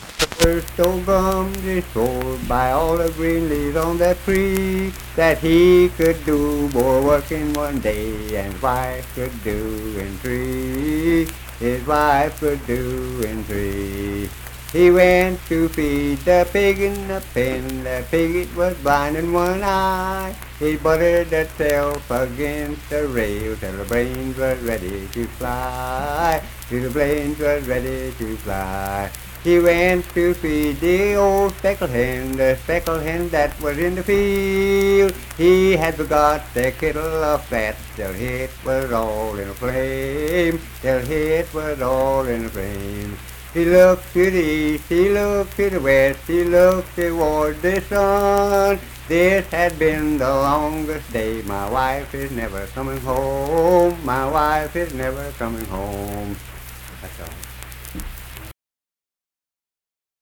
Unaccompanied vocal and banjo music
Voice (sung)